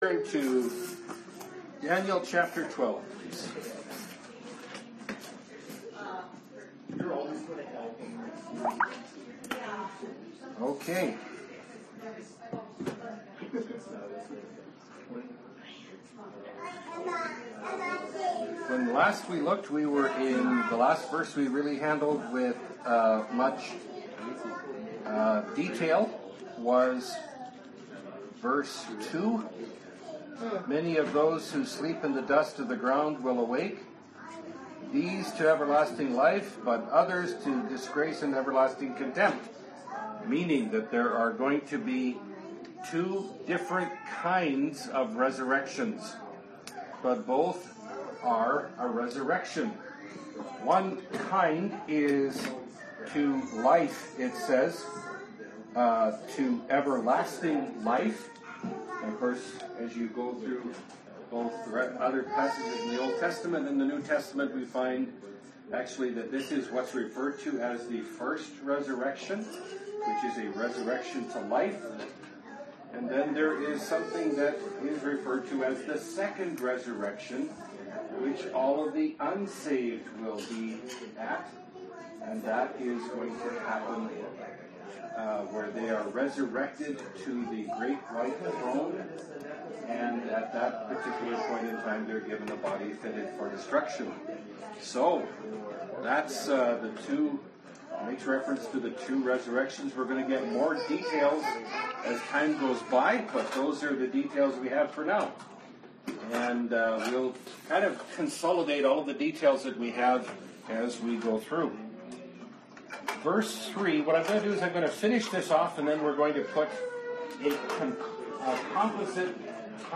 Bible Study – Daniel 12 – (2017)